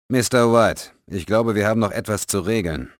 Including a licence to speak for the original German actors who provided the voices of Bond, M, Vesper, and LeChiffre.